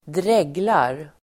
Ladda ner uttalet
dregla verb, dribble, droolGrammatikkommentar: A/x &Uttal: [²dr'eg:lar el. ²dr'e:glar] Böjningar: dreglade, dreglat, dregla, dreglarDefinition: (omedvetet) låta saliv rinna ur munnen
dreglar.mp3